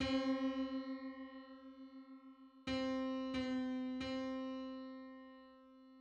Just: 525/512 = 43.41 cents.
Public domain Public domain false false This media depicts a musical interval outside of a specific musical context.
Five-hundred-twenty-fifth_harmonic_on_C.mid.mp3